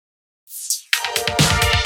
Fill 128 BPM (4).wav